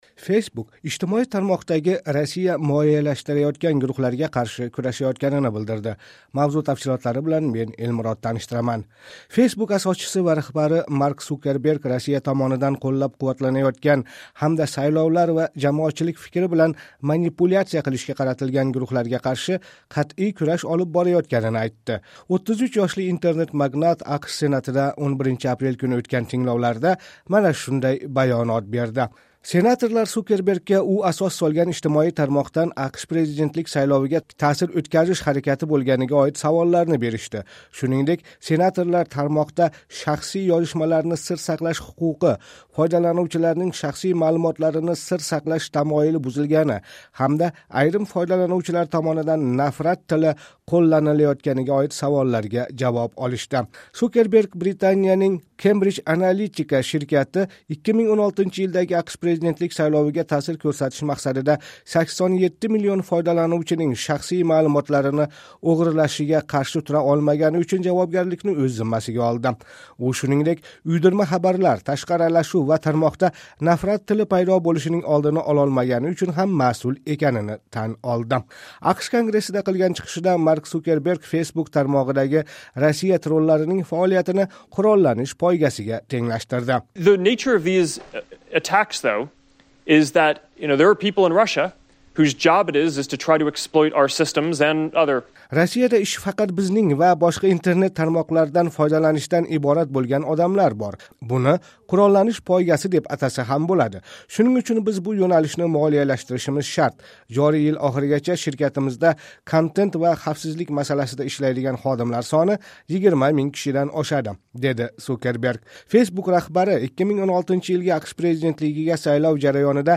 Mark Sukerberg AQSh Senatidagi tinglovda senatorlar savollariga javob berdi.